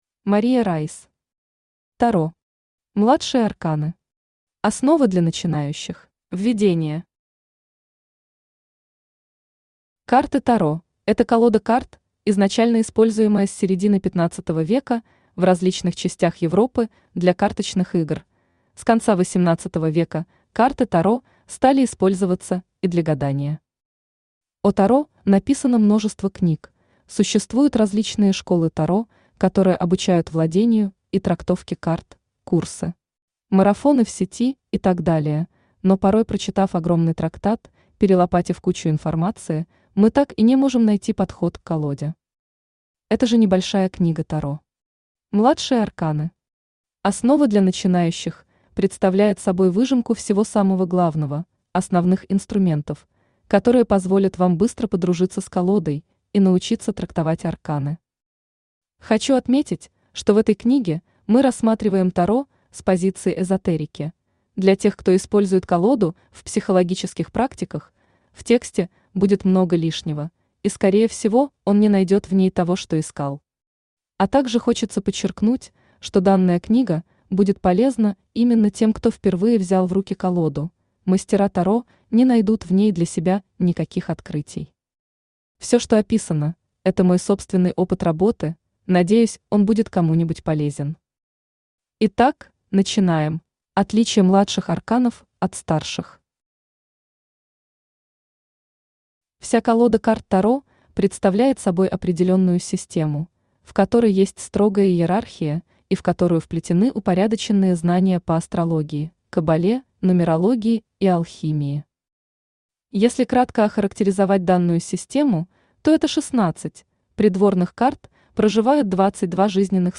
Основы для начинающих Автор Мария Райс Читает аудиокнигу Авточтец ЛитРес.